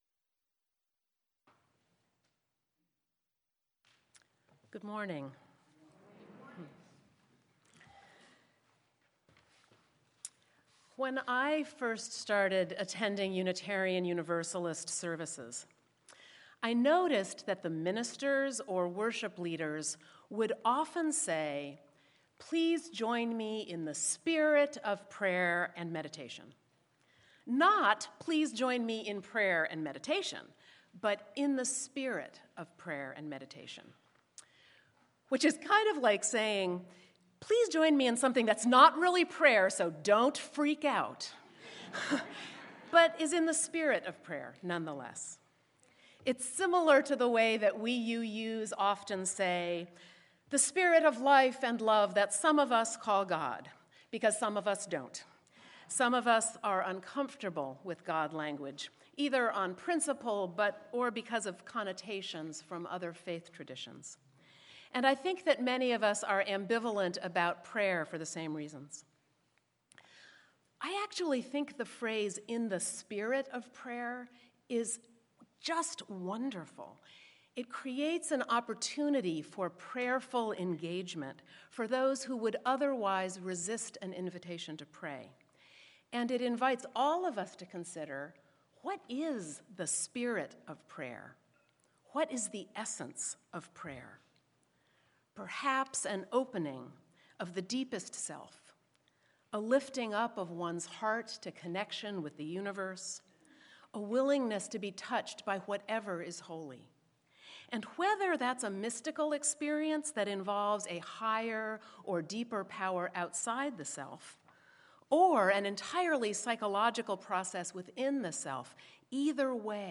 Sermon-In-the-Spirit-of-Prayer.mp3